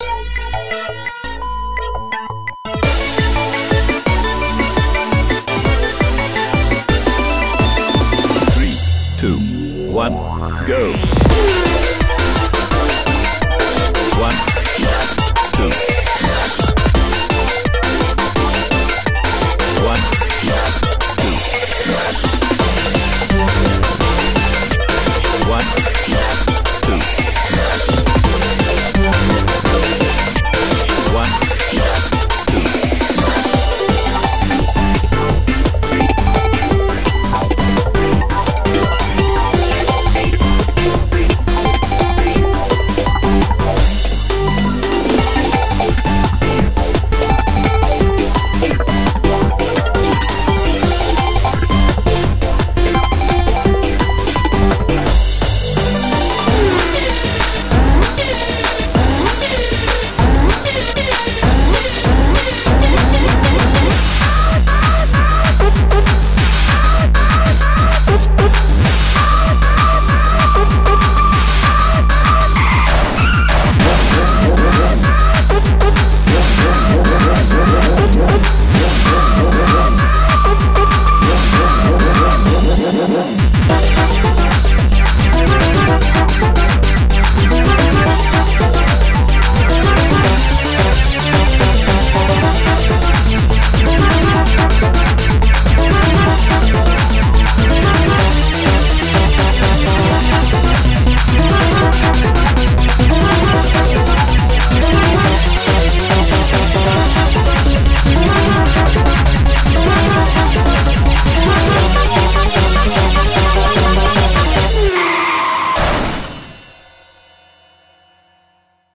BPM85-300
Audio QualityMusic Cut